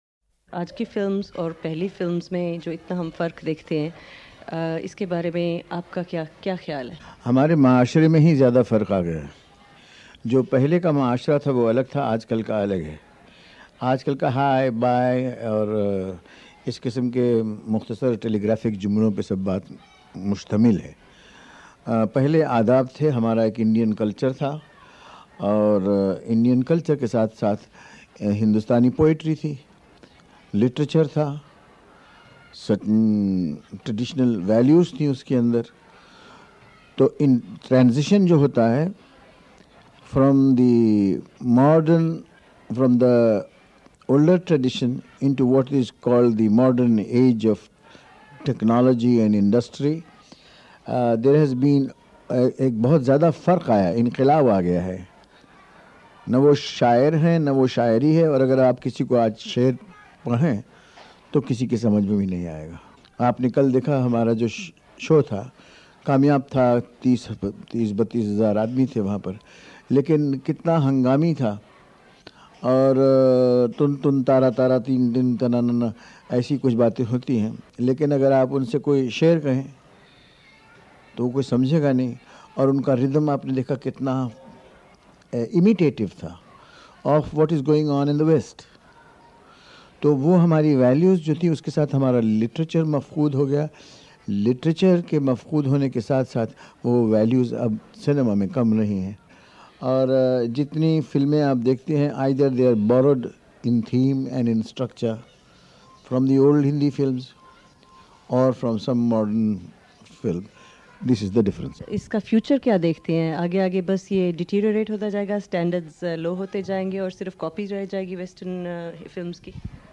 नब्बे के दशक में इस इंटरव्यू के दौरान दिलीप कुमार ने कई मुद्दों पर अपनी राय रखी थी.